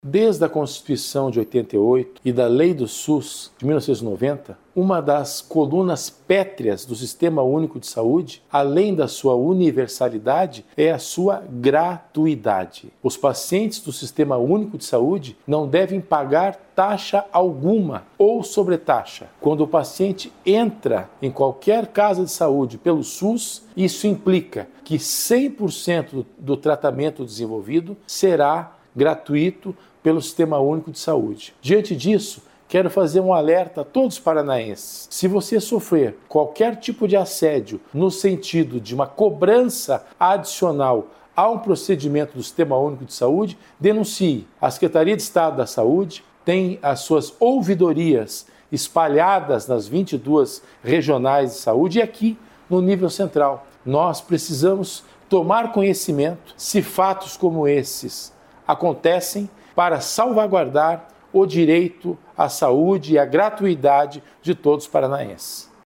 Sonora do diretor-geral da Sesa, César Neves, sobre a gratuidade dos serviços oferecidos pelo SUS